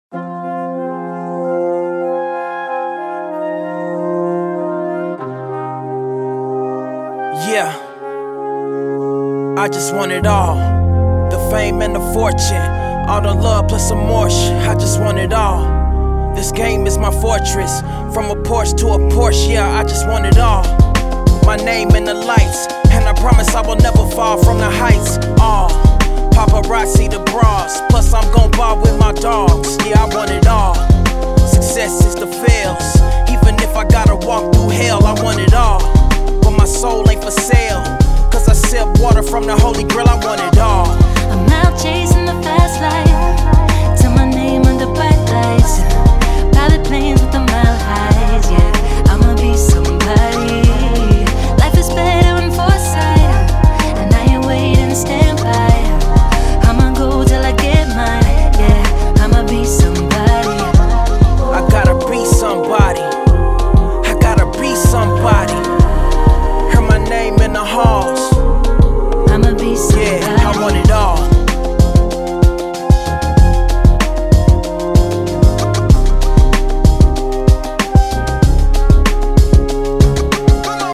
Hip Hop, R&B